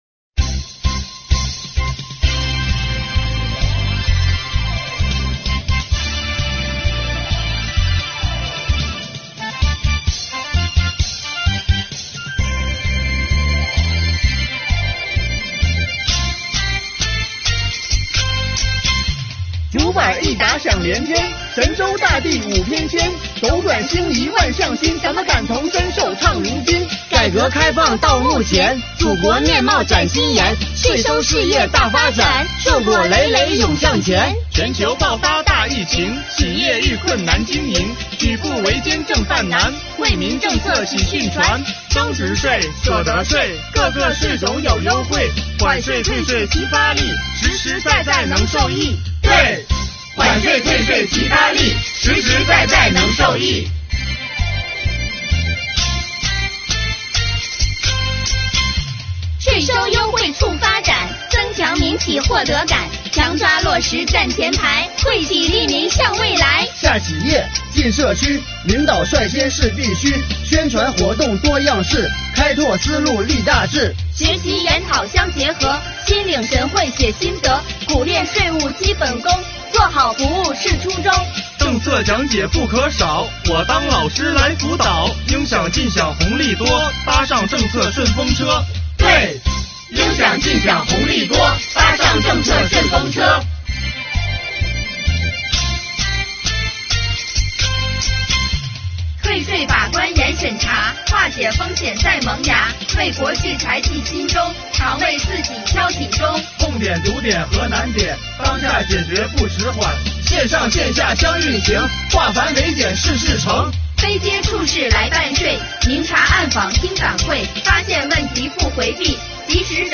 竹板一打响连天，营口税务人用“税务好声音”道出了他们立足本职、爱岗敬业的奉献精神和敢想敢为敢争先的新风貌。